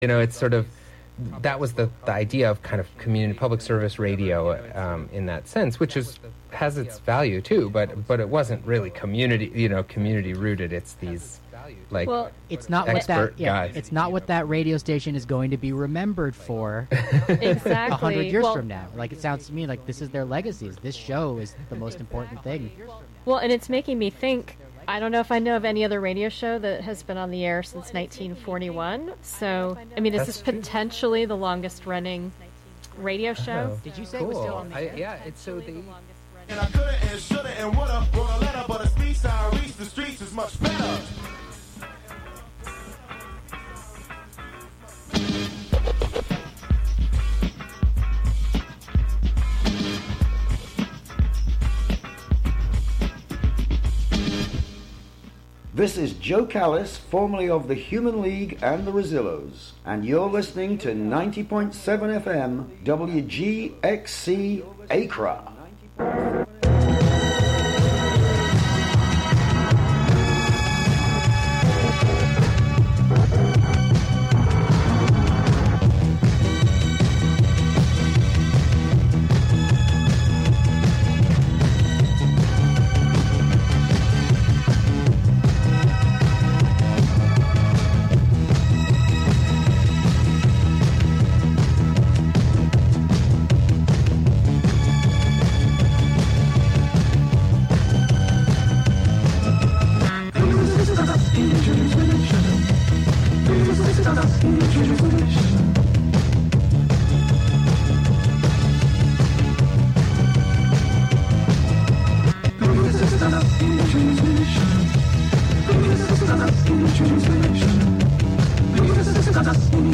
The latest episode of the radio theatre serial "I Have Seen Niagara" is featured.